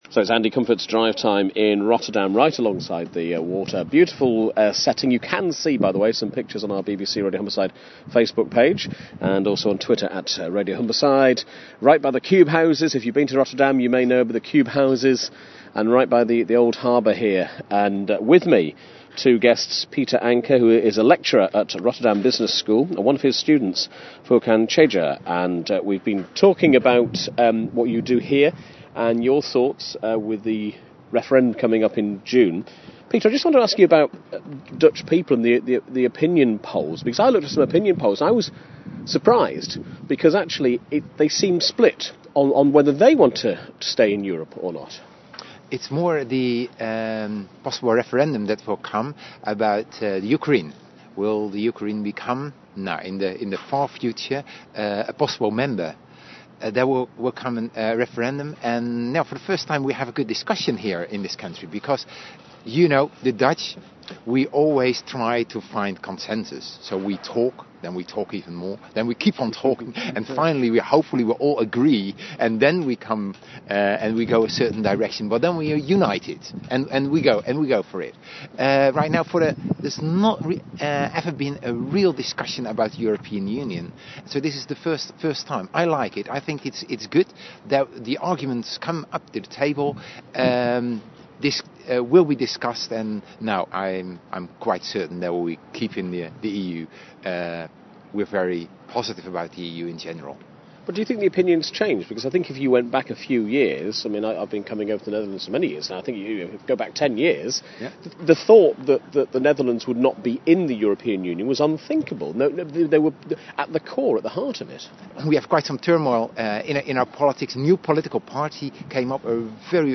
De BBC-radio zond een interview uit van Hogeschool Rotterdam docent en student over 'Brexit'.
Het interview was onderdeel van een live-uitzending van de BBC in de Rotterdamse Veerhaven.